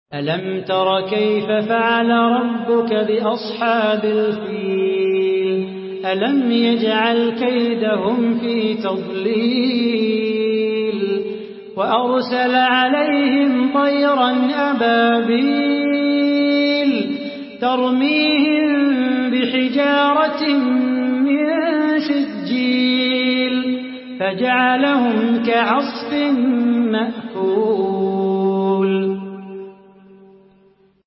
Surah আল-ফীল MP3 by Salah Bukhatir in Hafs An Asim narration.
Murattal Hafs An Asim